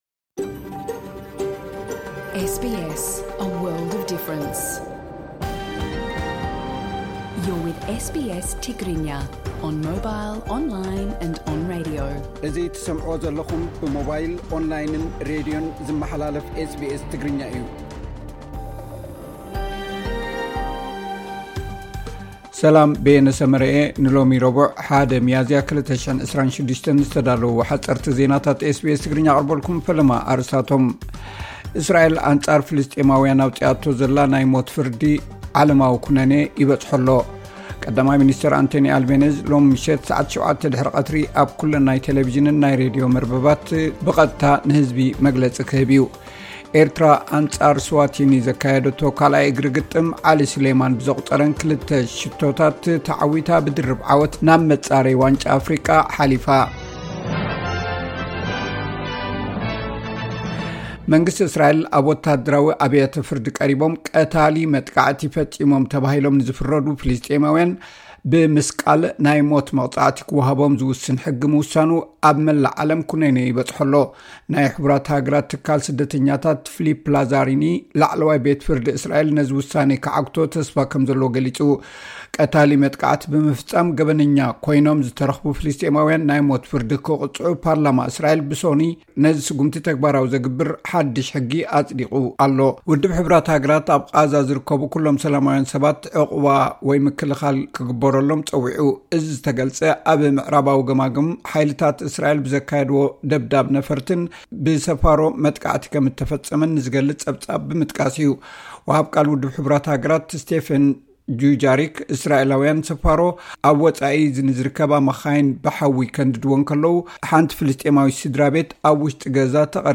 ሓጸርቲ ዜናታት SBS ትግርኛ (01 ሚያዝያ 2026)